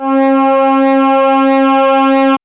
Index of /90_sSampleCDs/Vince_Clarke_Lucky_Bastard/SYNTHS/MULTIS
MOOG_B_6__L.wav